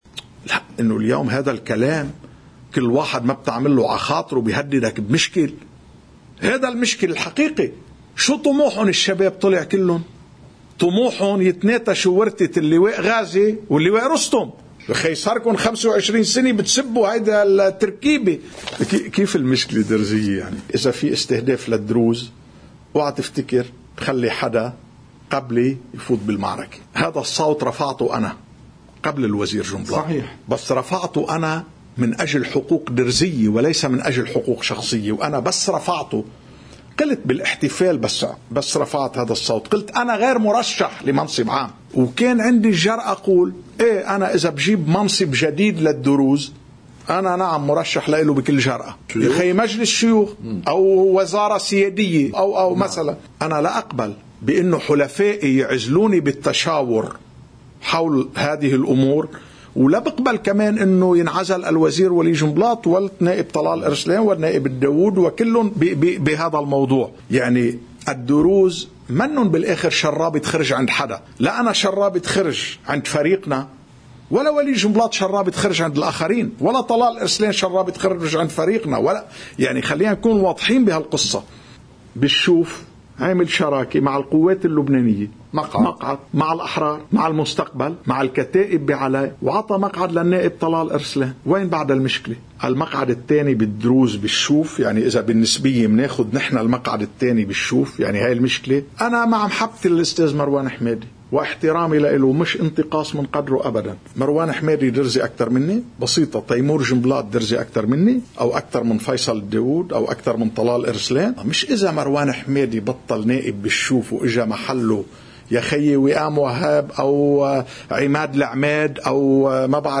مقتطف من حديث رئيس حزب التوحيد العربي وئام وهاب في حديث لقناة الـ”OTV”: